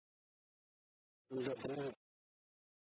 uitspraak Le jardin uitspraak Le jardin